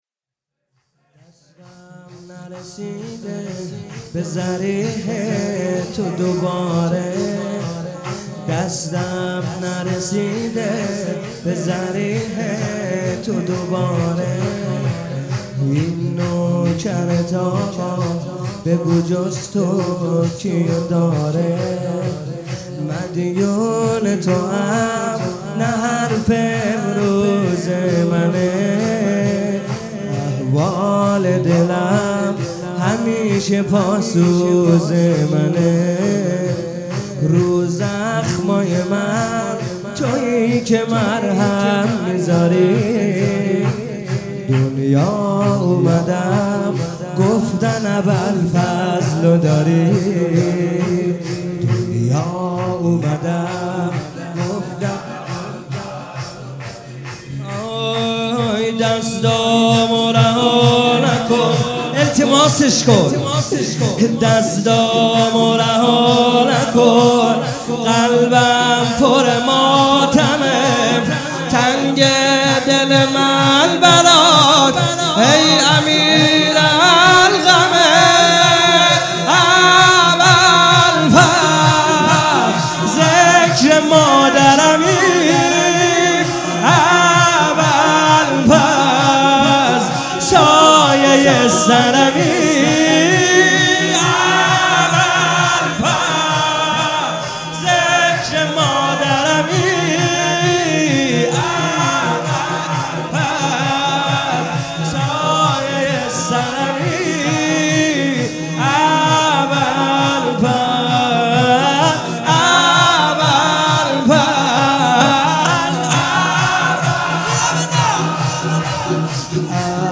شب 10 فاطمیه 95 - شور - دستم نرسیده به ضریح تو